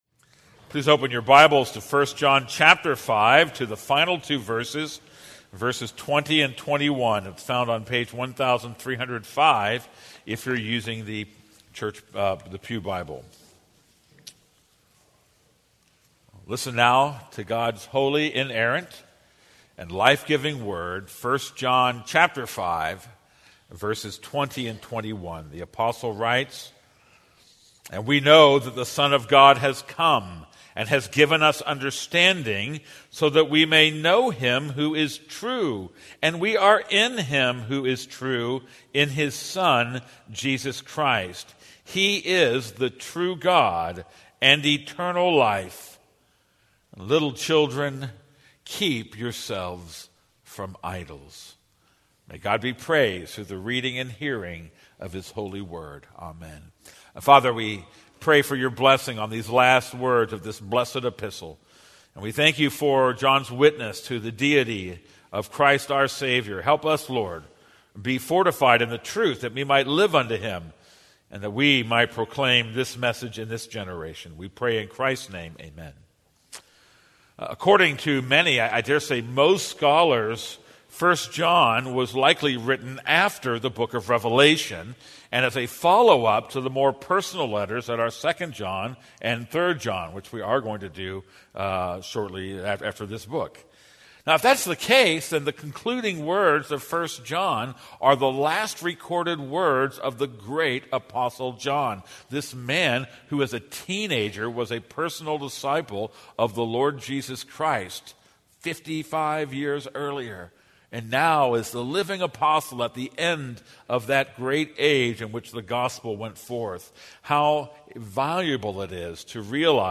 This is a sermon on 1 John 5:20-21.